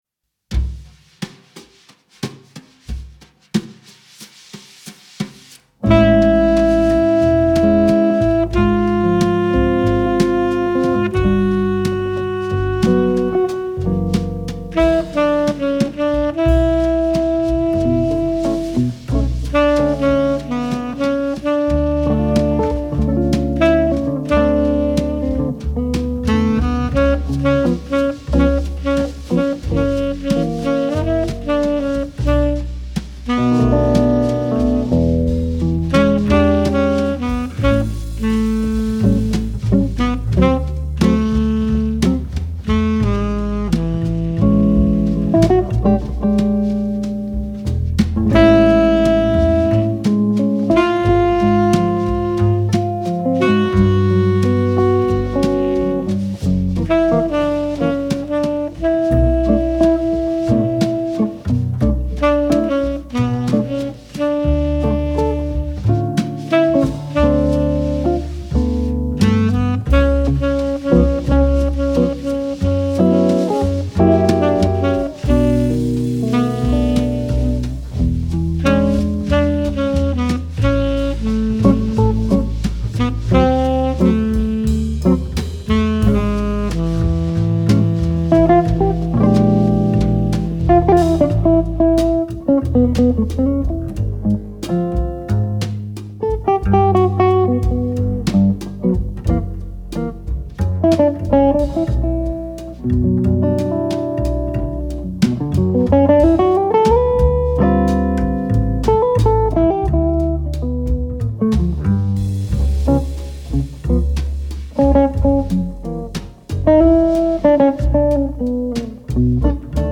آرامش بخش
جاز موزیک